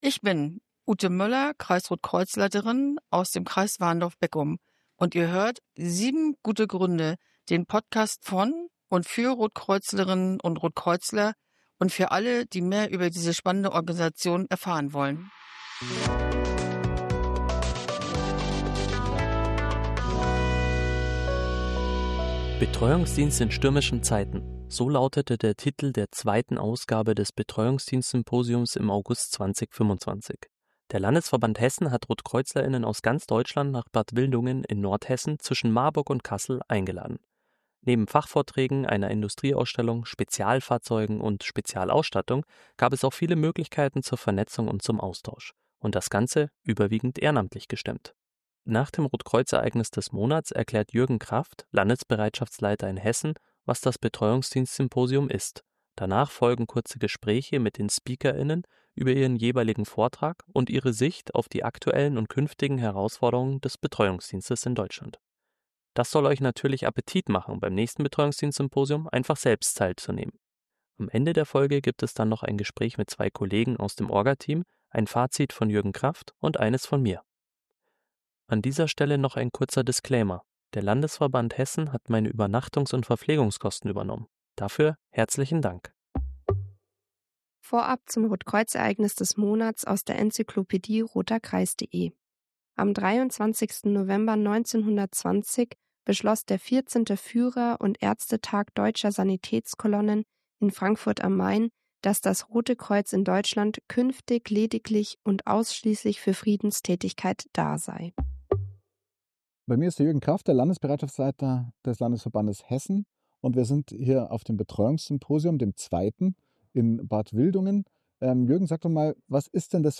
Danach folgen kurze Gespräche mit den Speaker:innen über ihren jeweiligen Vortrag und ihre Sicht auf die aktuellen und künftigen Herausforderungen des Betreuungsdienstes in Deutschland.